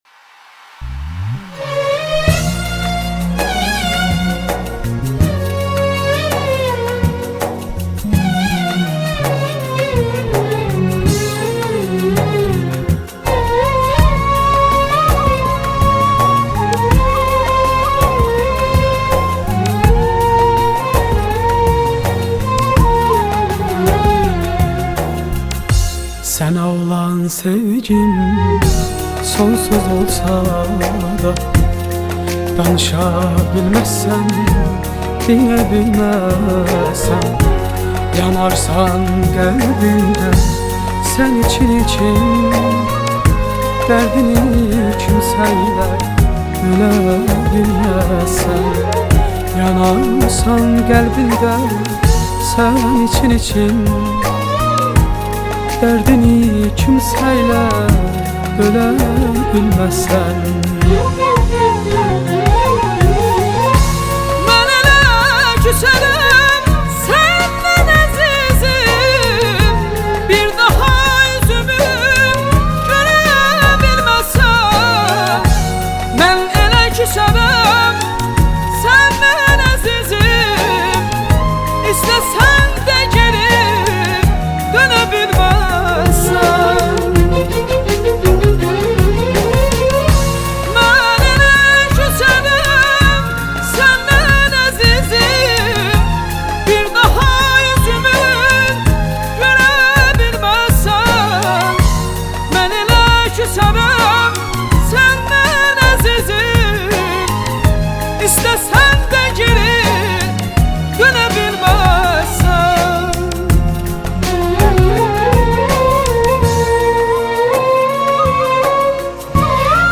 آهنگ آذربایجانی